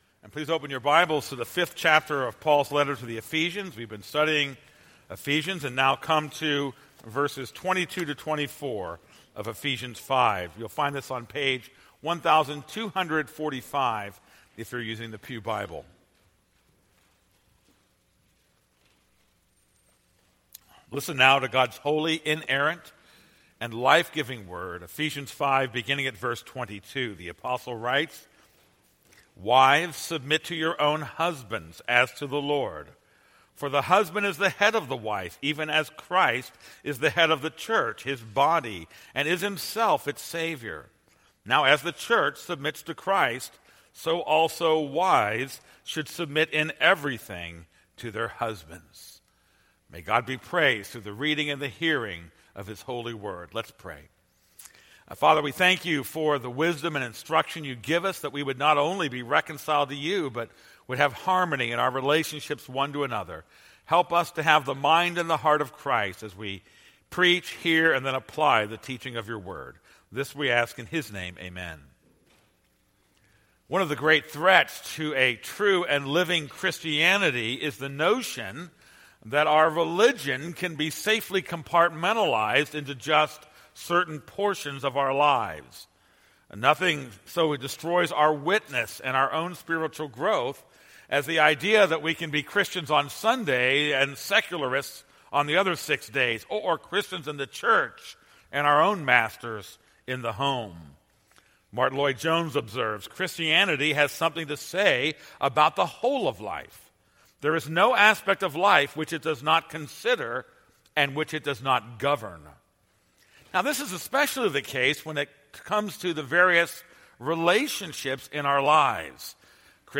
This is a sermon on Ephesians 5:22-24.